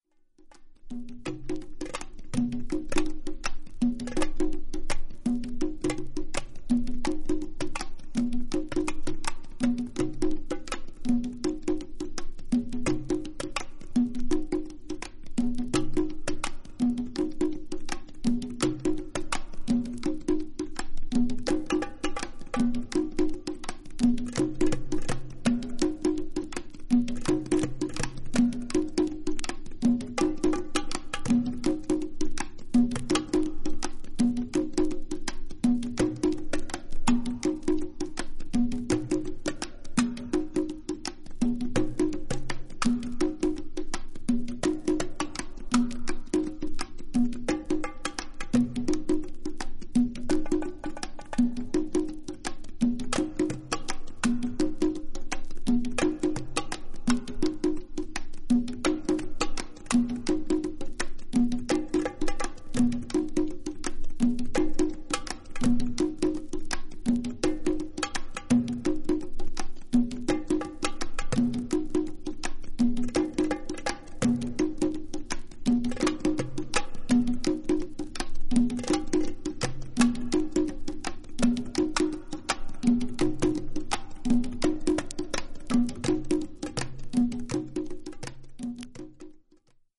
プリミティブでトライバルなリズムの洪水に、ある種のトランス状態へと誘われる、DJ〜トラック・メイカー必須のアルバムです。